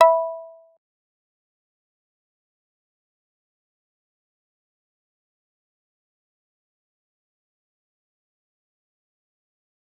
G_Kalimba-E5-pp.wav